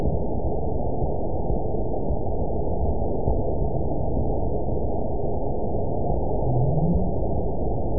event 920575 date 03/30/24 time 23:30:41 GMT (1 year, 1 month ago) score 9.50 location TSS-AB02 detected by nrw target species NRW annotations +NRW Spectrogram: Frequency (kHz) vs. Time (s) audio not available .wav